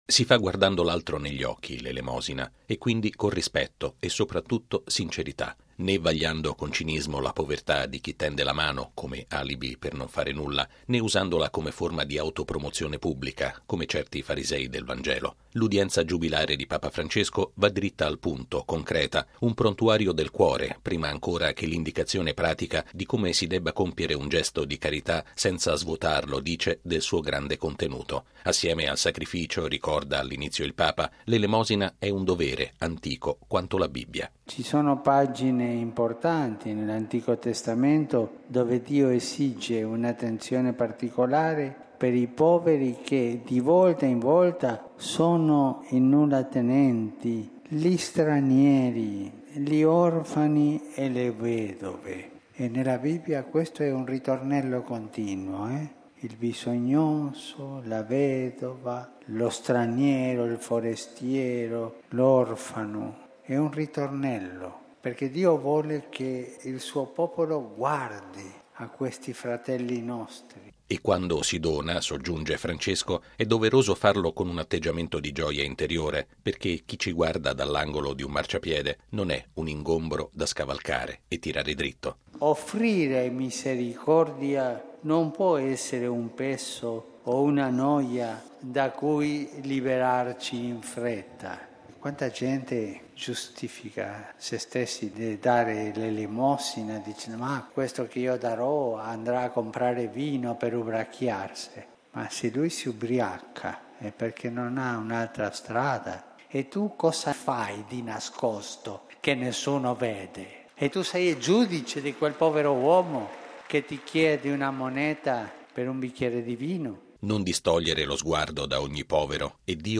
Papa Francesco ha parlato di questo aspetto concreto della vita di fede durante l’udienza giubilare del sabato, tenuta in Piazza San Pietro davanti oltre 40 mila persone. Il servizio